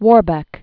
(wôrbĕk), Perkin 1474?-1499.